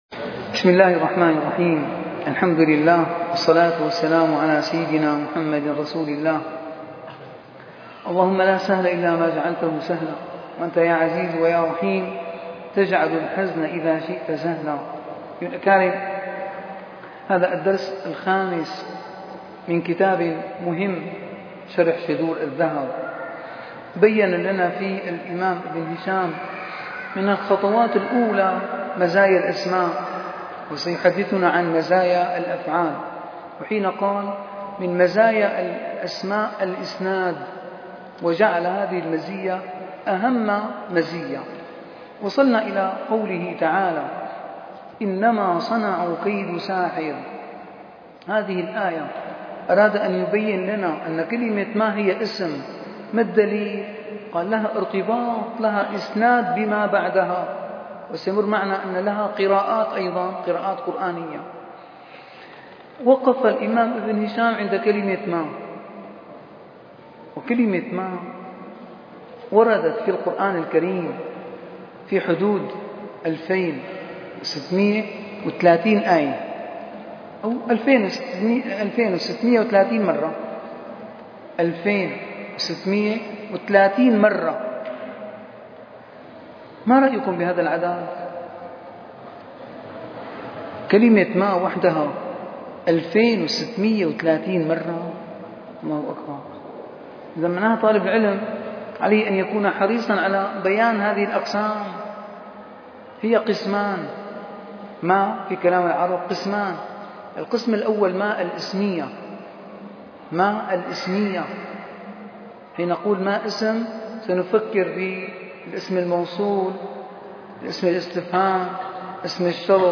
- الدروس العلمية - شرح كتاب شذور الذهب - 5- شرح كتاب شذور الذهب: مزايا الأسماء وأنواع الفعل